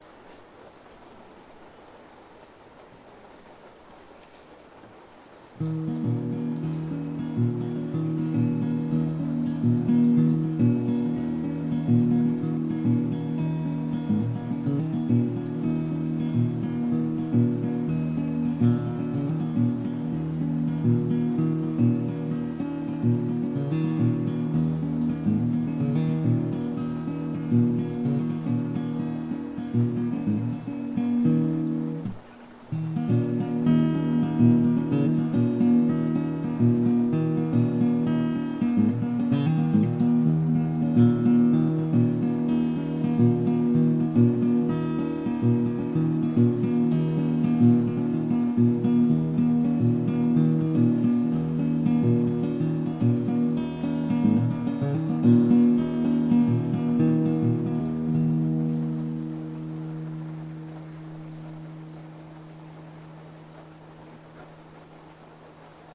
Bongo.m4a